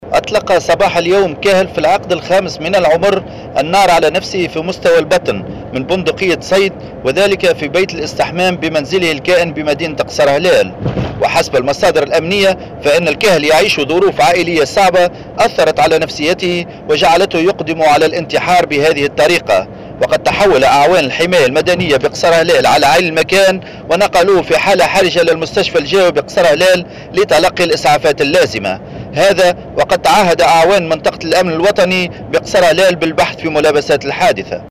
مراسلنا في قصر هلال